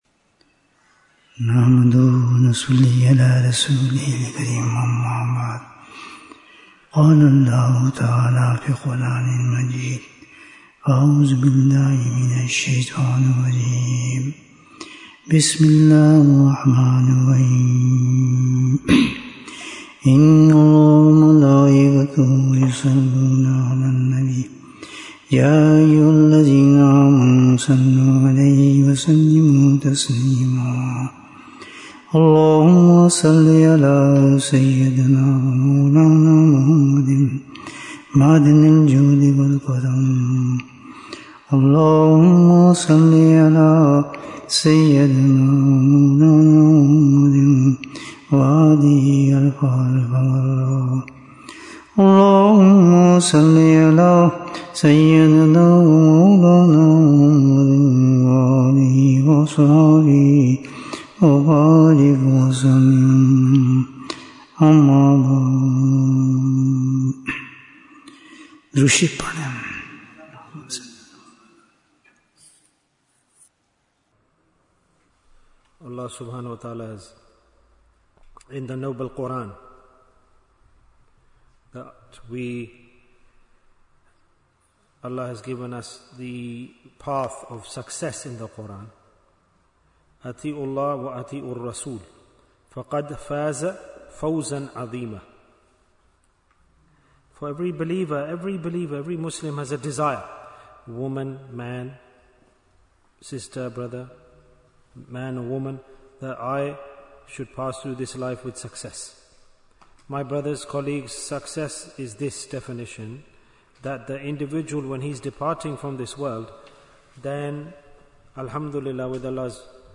Bayan, 47 minutes10th July, 2025